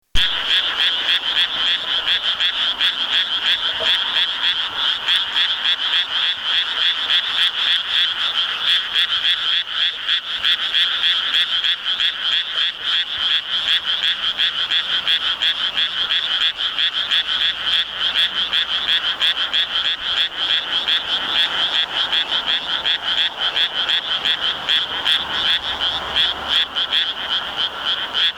アマガエル（voice) - 神奈川県大井町ホームページ